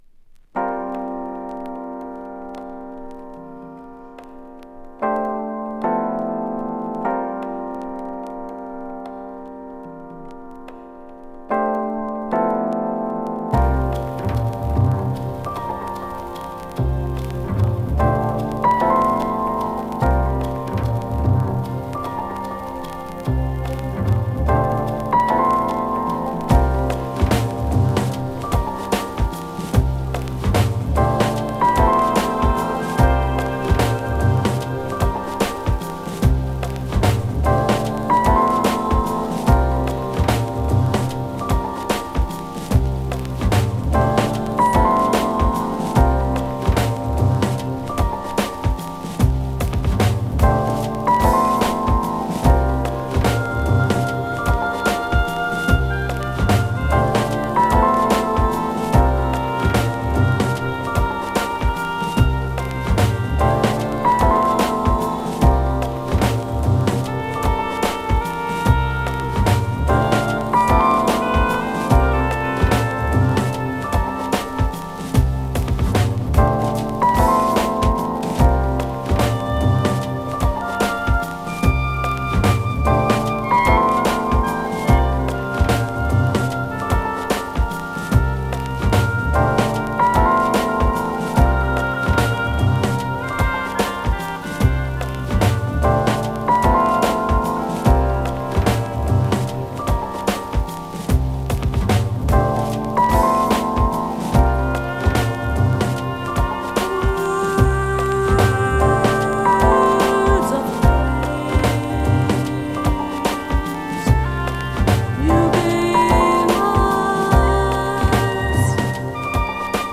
> JAZZY BREAK/ELECTRONICA/ABSTRACT